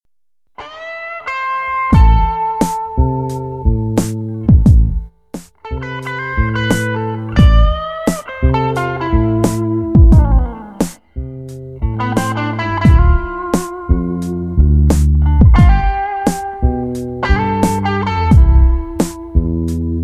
instrumental_unbekannt_cut_mp3.mp3